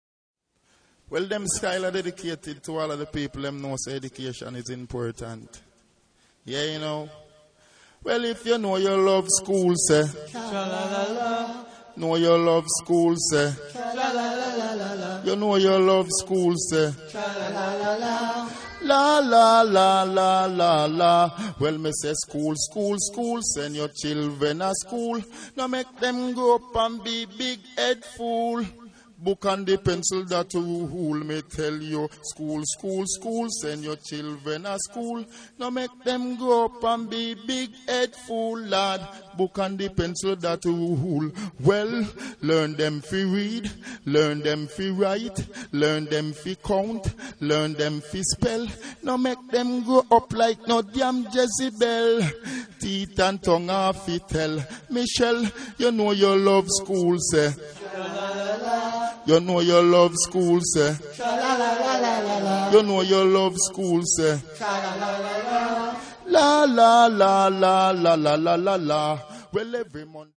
Hear the voices: